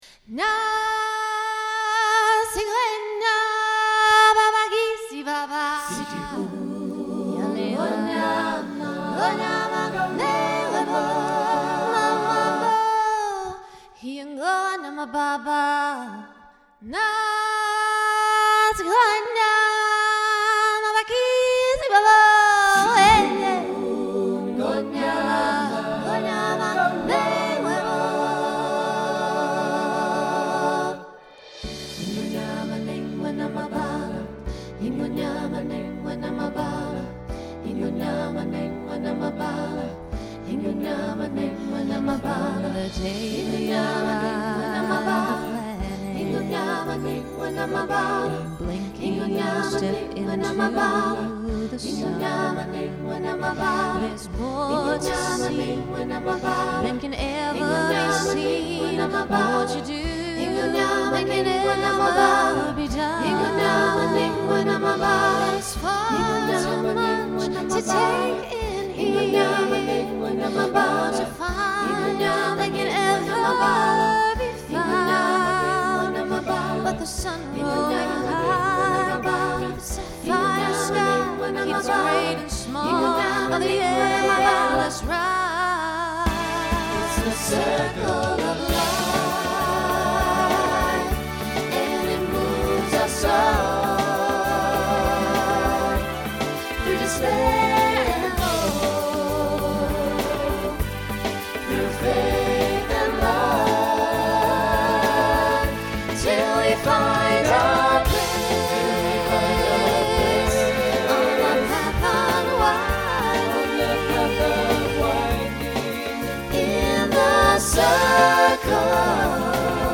Voicing SATB Instrumental combo Genre Broadway/Film